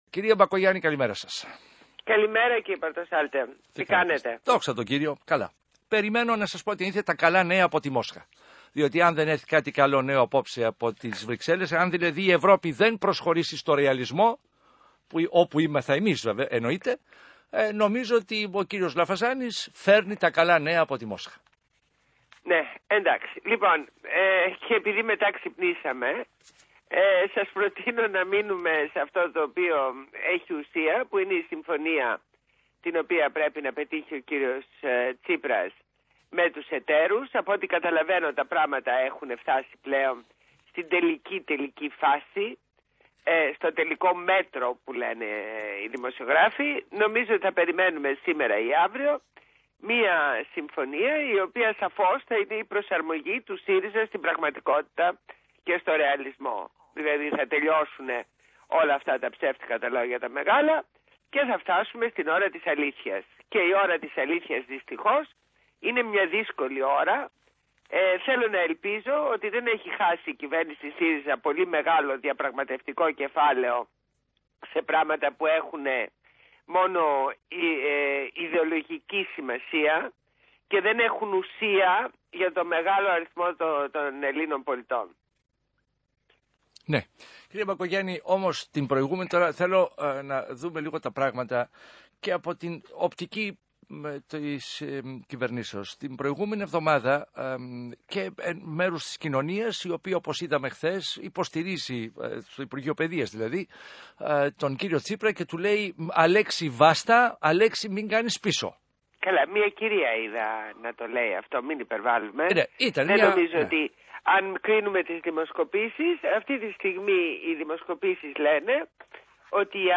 Συνέντευξη στο ραδιόφωνο του ΣΚΑΙ
Ακούστε εδώ τη ραδιοφωνική συνέντευξη στην εκπομπή του Α. Πορτοσάλτε στο ΣΚΑΙ.